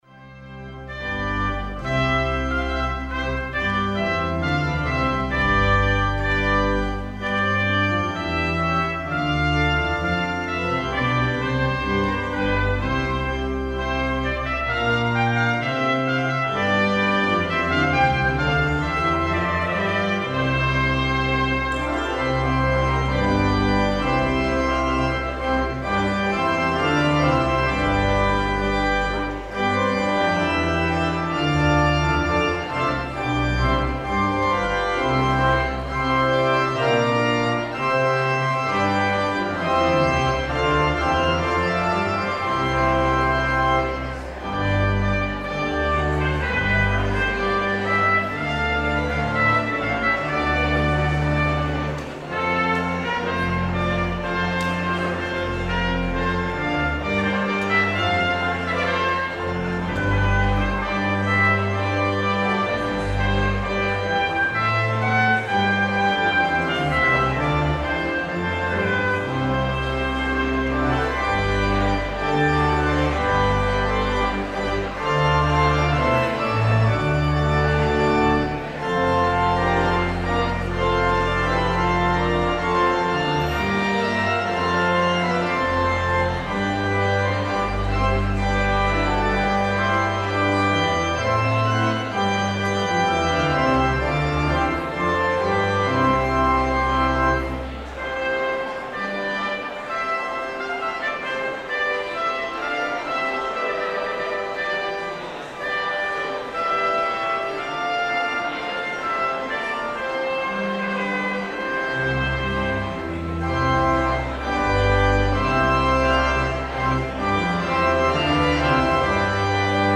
trumpet
organ